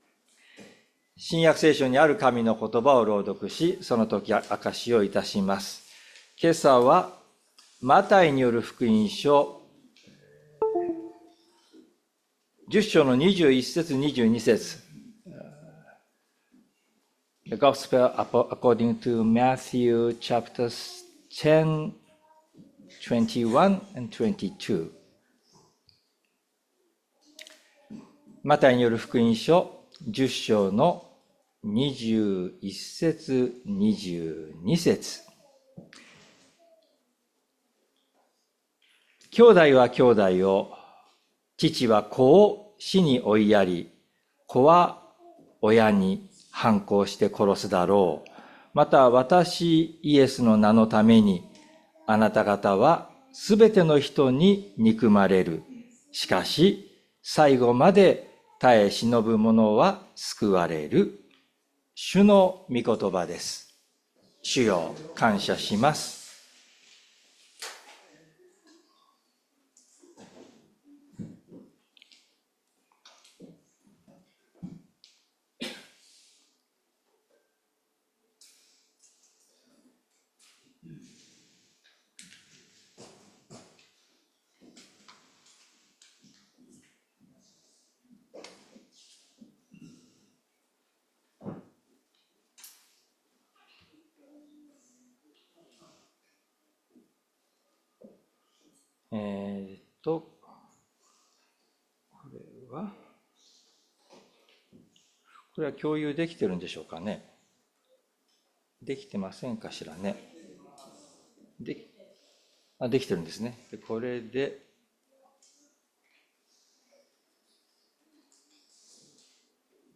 日曜朝の礼拝
礼拝説教を録音した音声ファイルを公開しています。